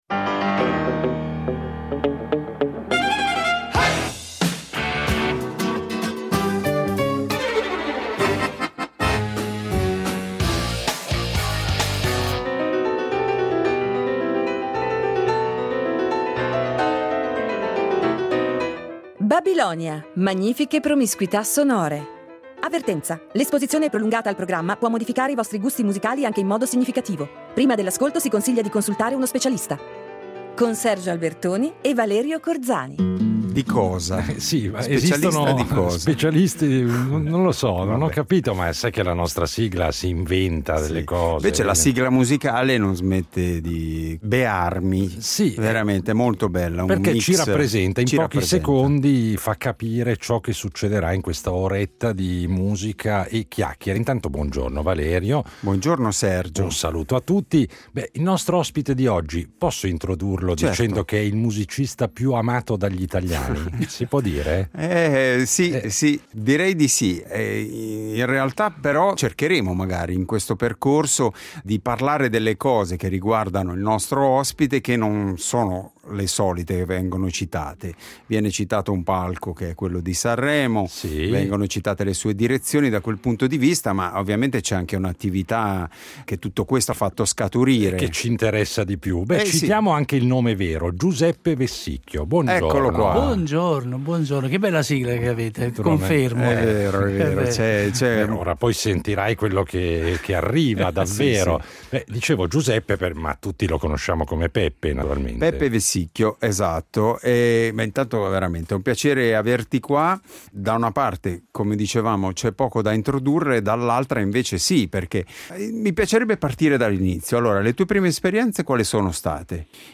Il nostro ospite di oggi è un polistrumentista napoletano, arrangiatore, direttore d’orchestra, compositore e personaggio televisivo tra i più amati dal grande pubblico italiano.